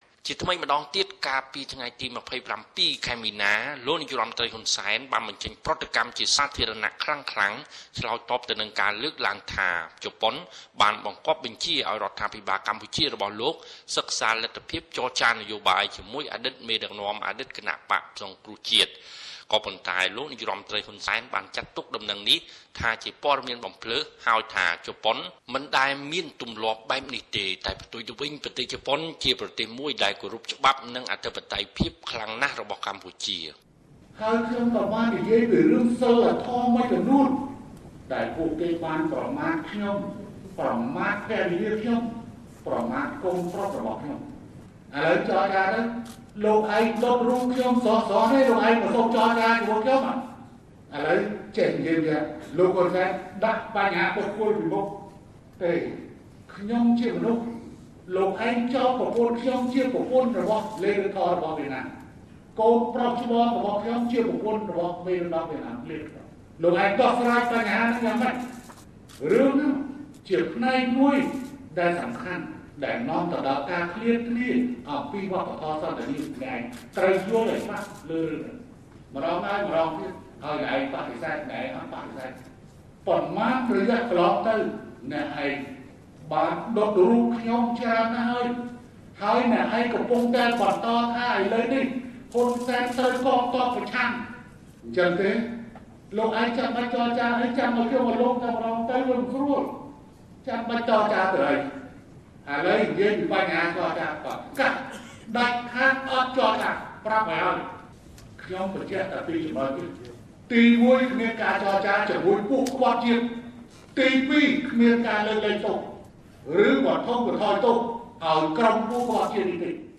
( សំឡេង លោកនាយករដ្ឋមន្ត្រី ហ៊ុនសែន )